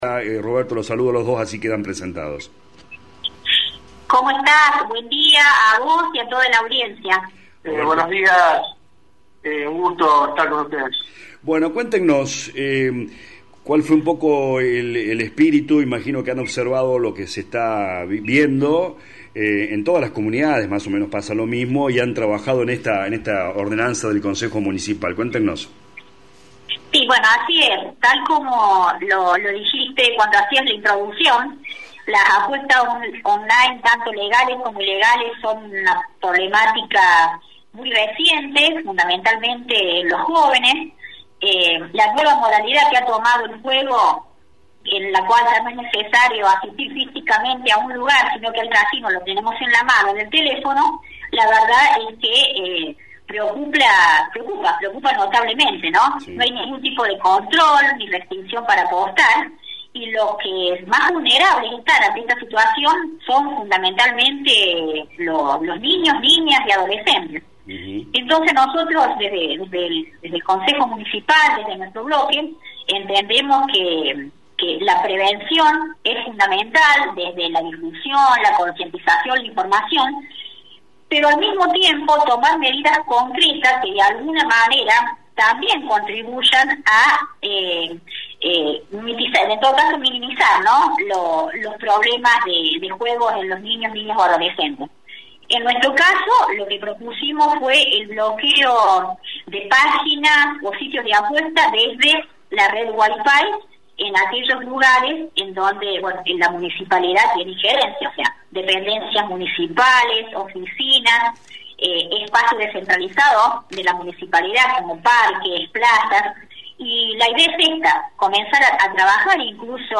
LA RADIO 102.9 habló con los concejales Lic. Claudia Echazarreta y el Dr. Roberto Pelussi quienes explican los alcances de la ordenanza…